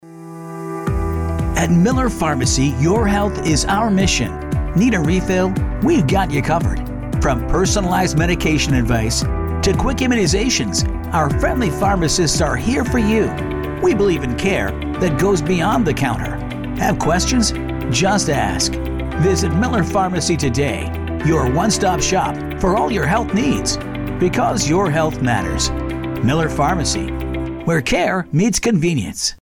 ad samples.
Pharmacy-Commercial-Updated.mp3